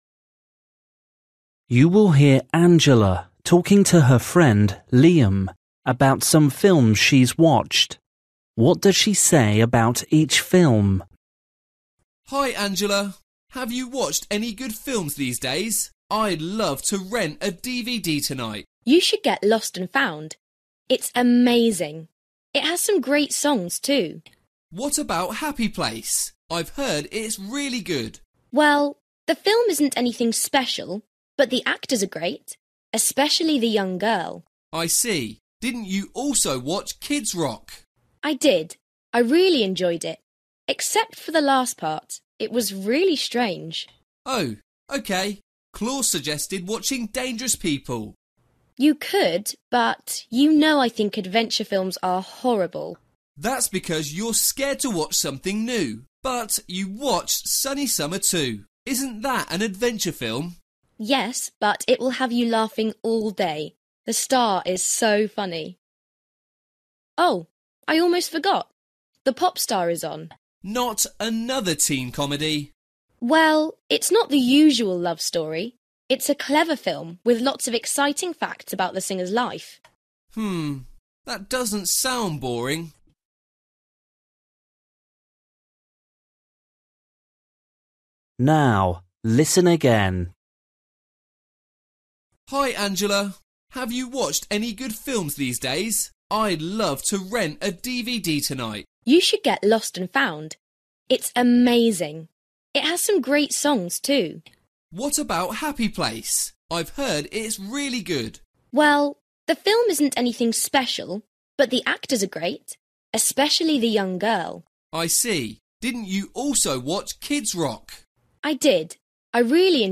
Bài tập luyện nghe tiếng Anh trình độ sơ trung cấp – Nghe cuộc trò chuyện và chọn câu trả lời đúng phần 25